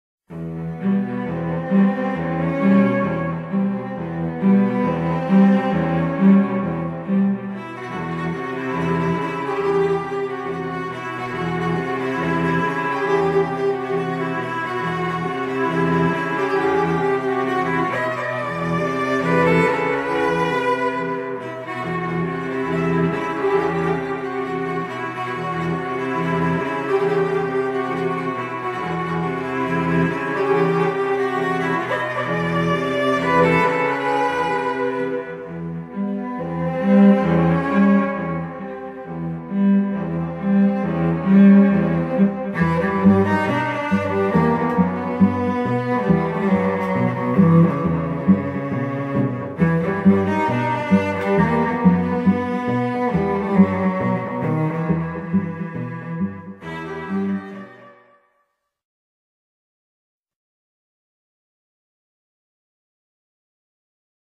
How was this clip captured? • Fully acoustic - can play anywhere